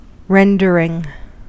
Ääntäminen
US : IPA : [ˈrɛn.dər.ɪŋ]